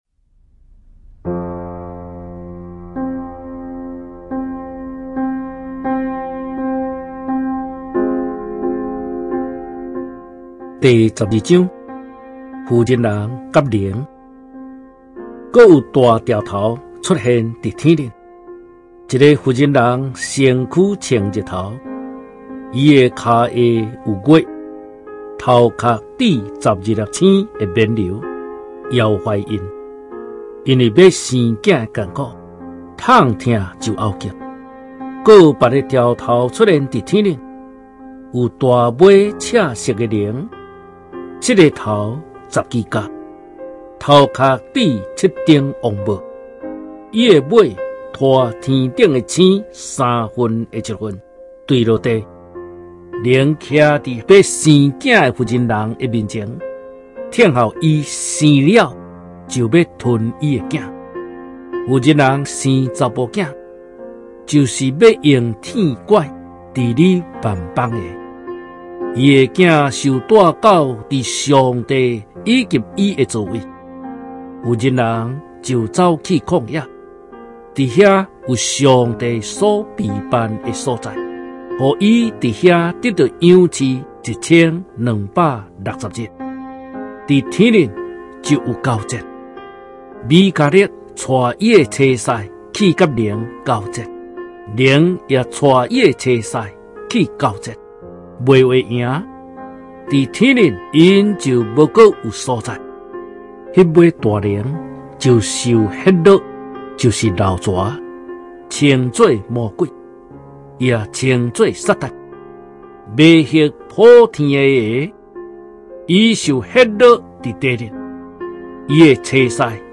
台語新約(台灣長老教會傳播中心授權)有聲聖經 啟示錄 12章